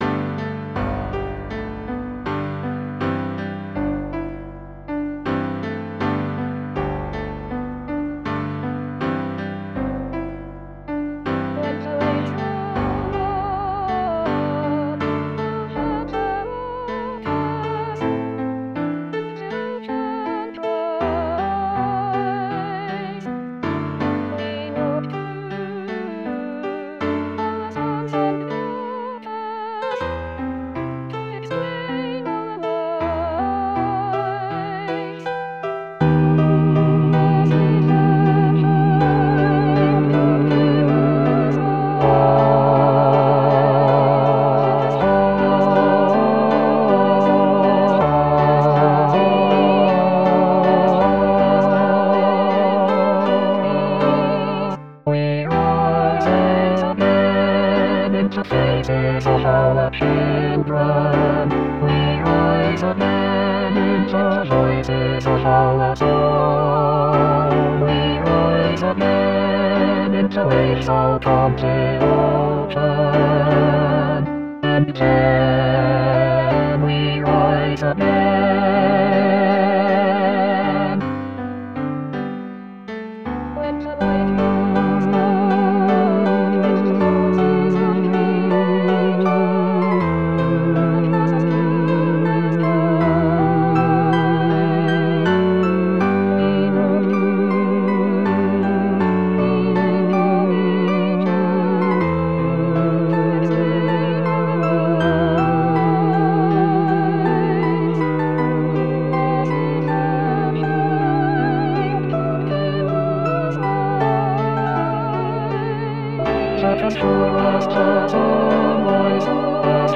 Bass Bass 1